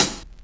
Dusty Closed Hat 05.wav